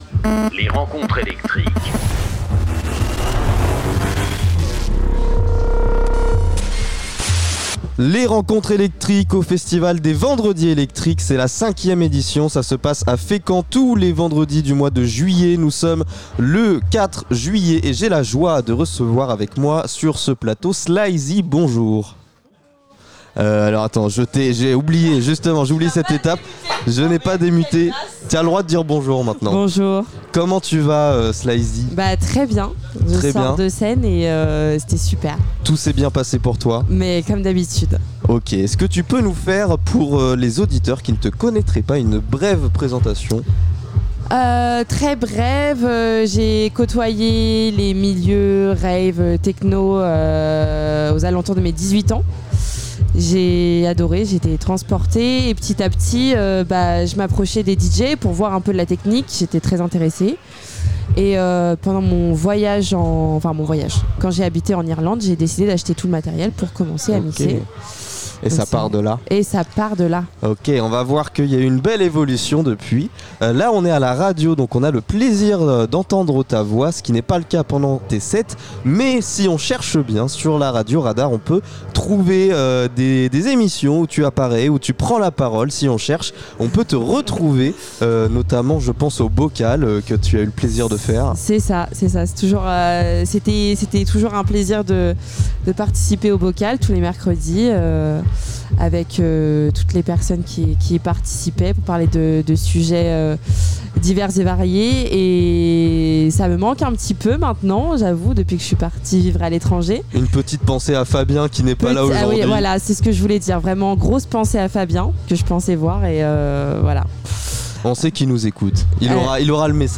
Les rencontres électriques sont les interviews des artistes régionaux qui se produisent lors du festival "Les vendredi électriques" organisés par l'association Art en Sort.